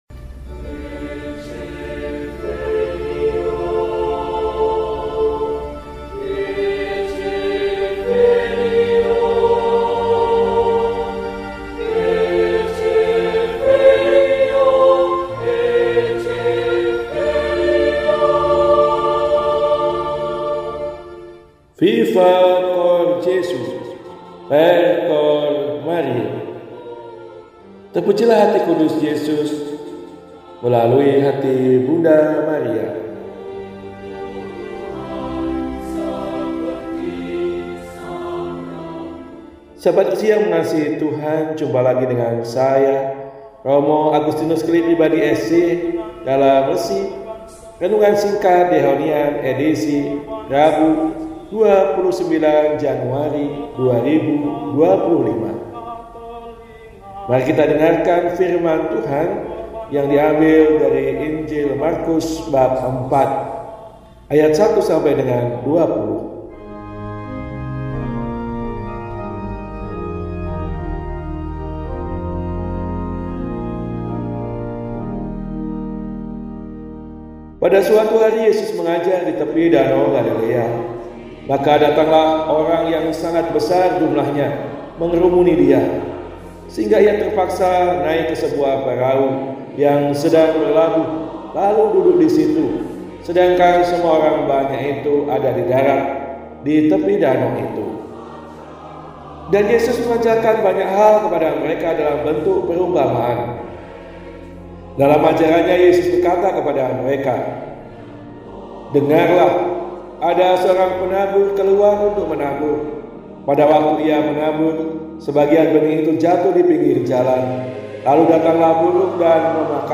Rabu, 29 Januari 2025 – Hari Biasa Pekan III – RESI (Renungan Singkat) DEHONIAN